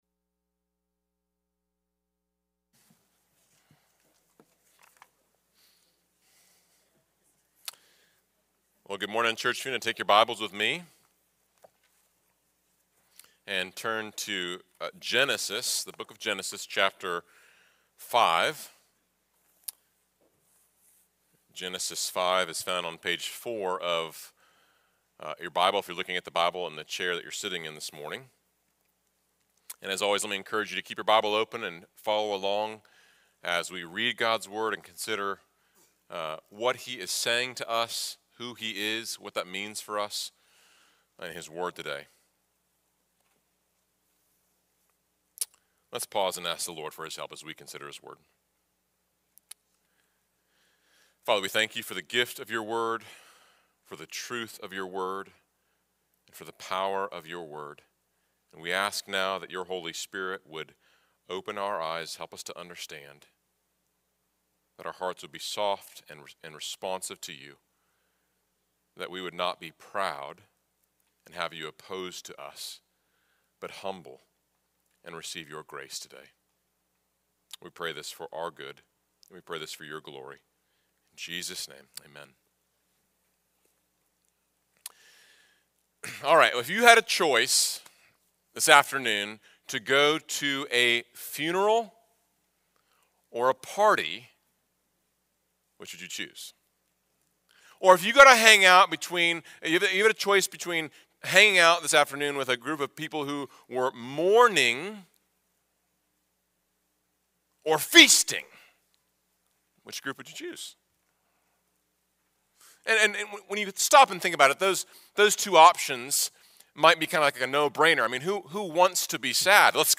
FBC Sermons